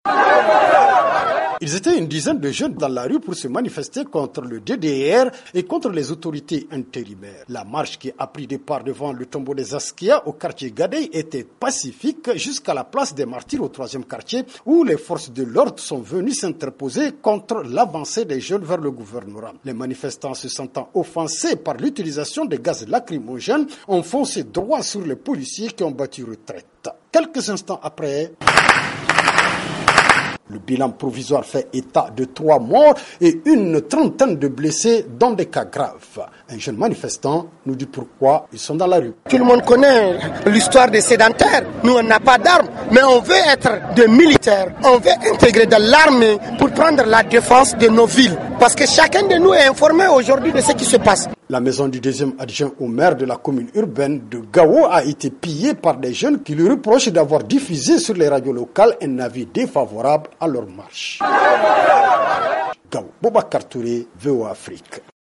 Des morts dans une manifestation violente des jeunes à Gao, un reportage